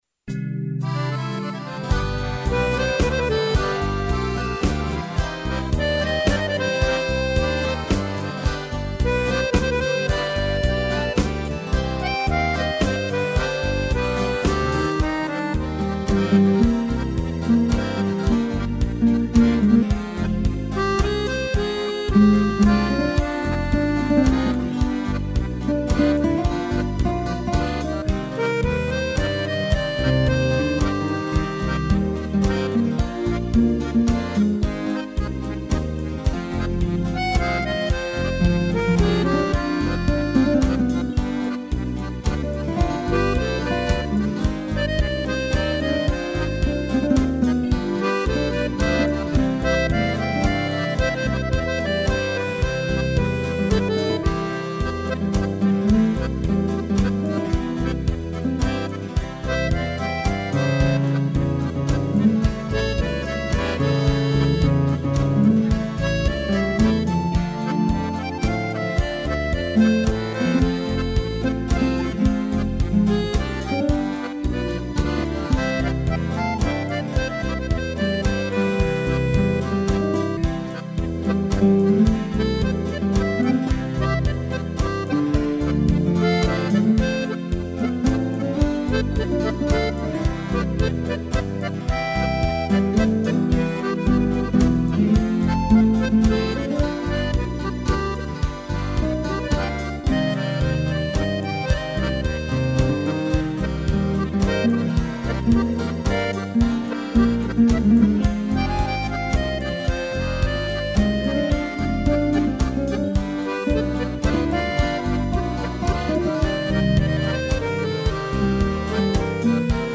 acordeão
(instrumental)